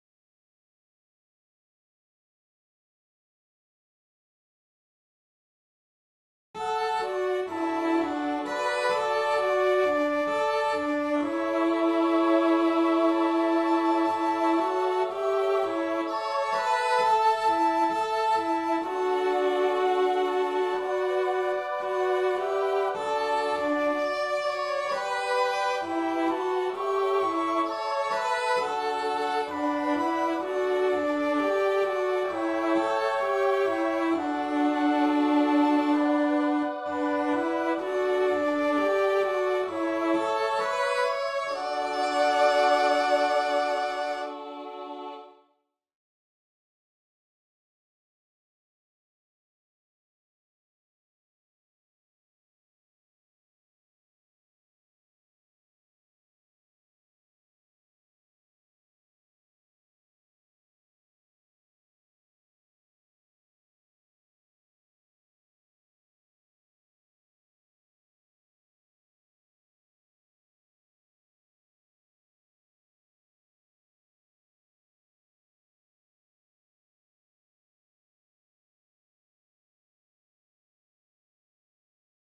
Lahdevetta-hangen-alla-alin-aani-poissa.mp3